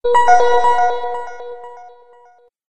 SNS（ソーシャル・ネットワーキング・サービス）に通知音にぴったりな長さの音。